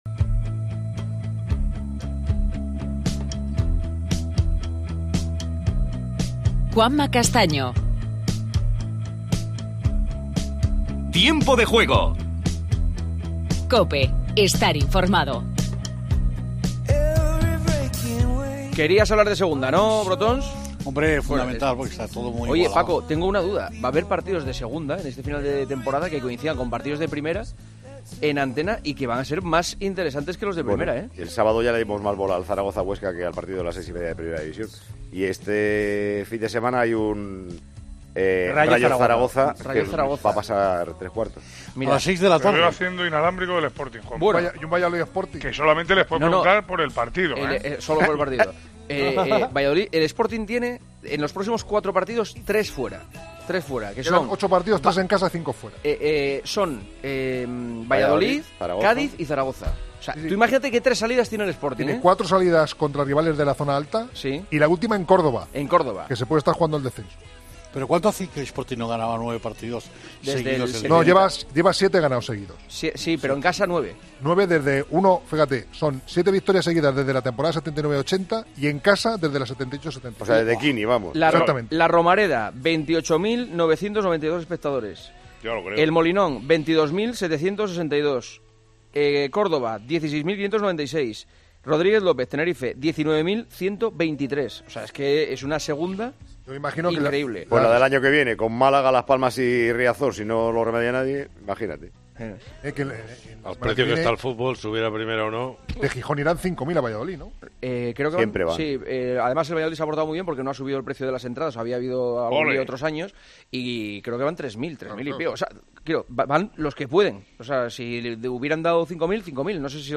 Las preguntas de los oyentes. Últimos coletazos del GP de Argentina de Motociclismo y del GP de Bahrein de Fórmula Uno.